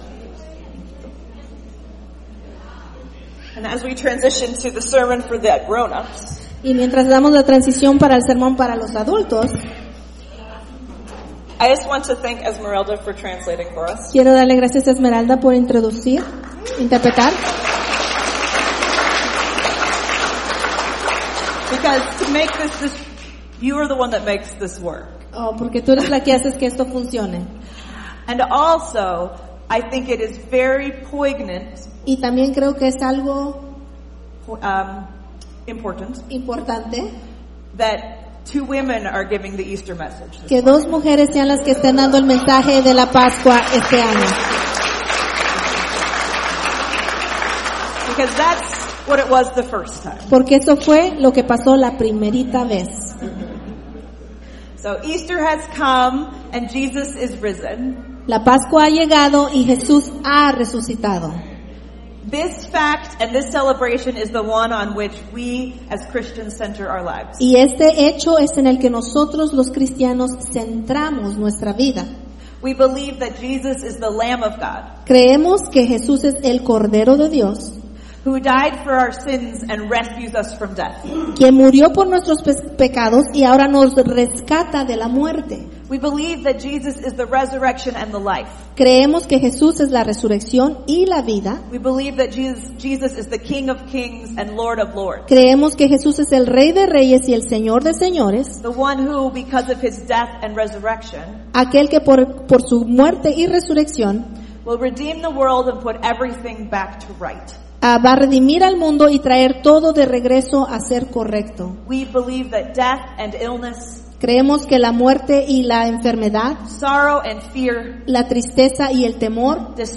April 5, 2026 – English & Spanish Easter Sunday